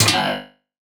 Hit for enemy 2.wav